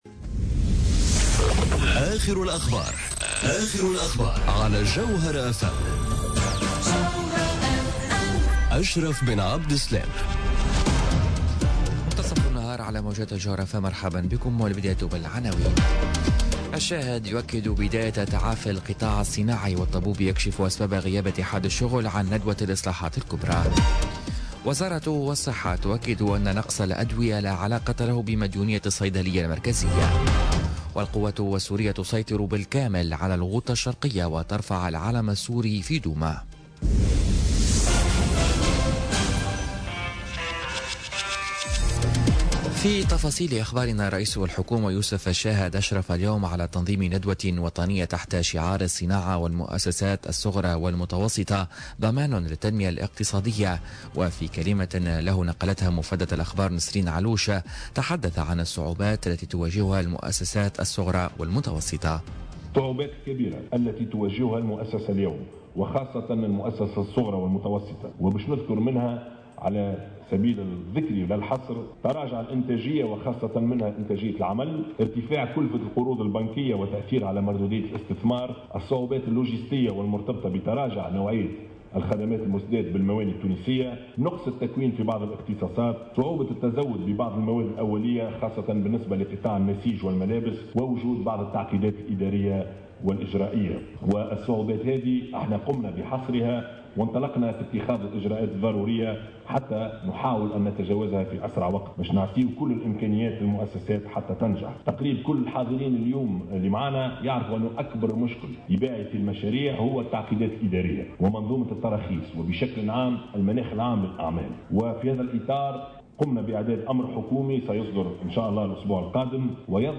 نشرة أخبار منتصف النهار ليوم الخميس 12 أفريل 2018